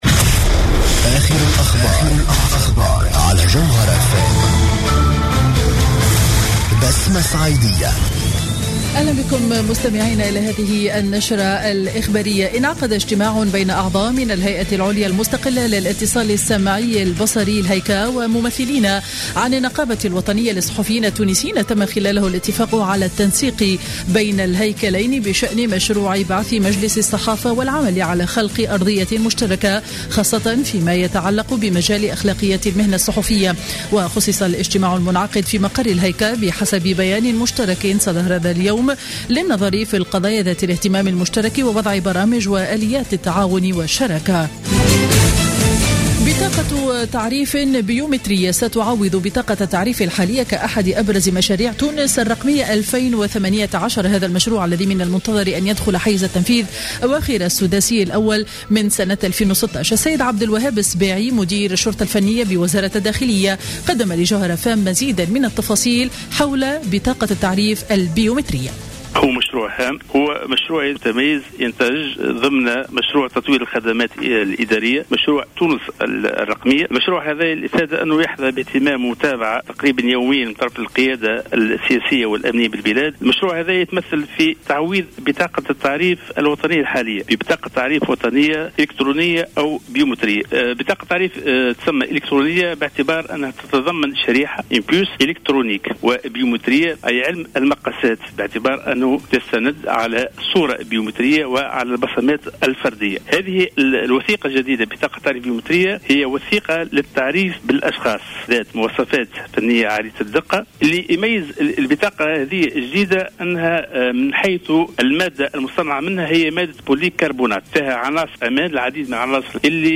نشرة أخبار السابعة مساء ليوم الثلاثاء 11 أوت 2015